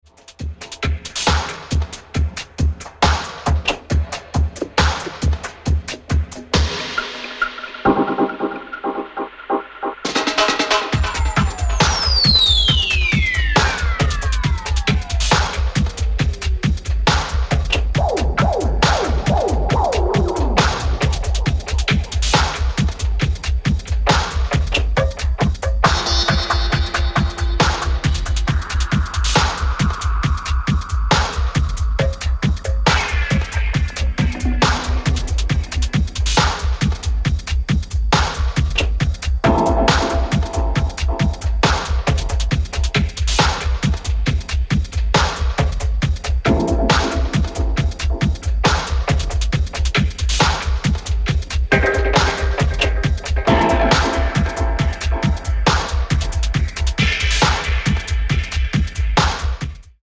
Dark and deeply dubby